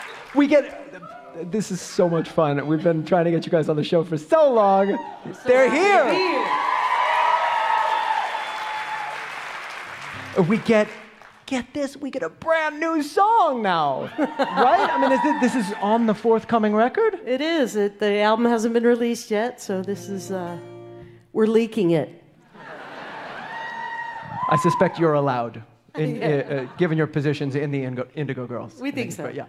lifeblood: bootlegs: 2020-02-15: the town hall - new york, new york (live from here with chris thile)
(captured from a youtube live stream)
03. interview (0:29)